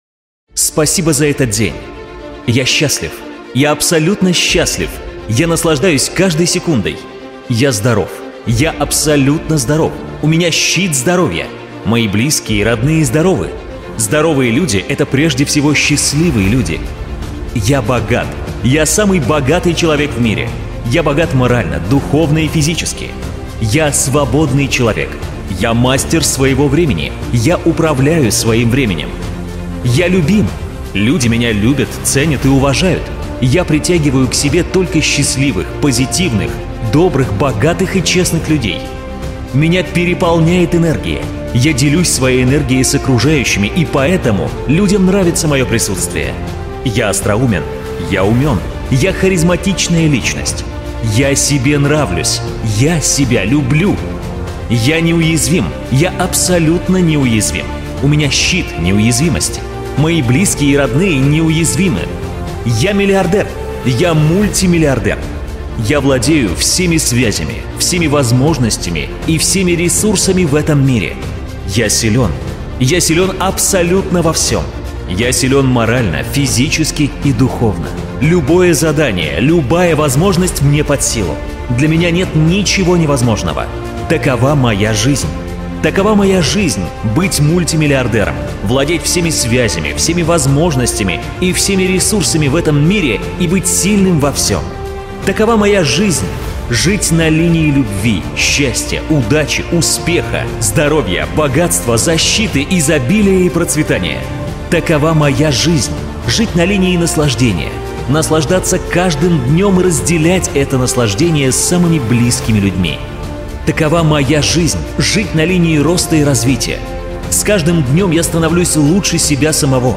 Мощная аффирмация для достижения успеха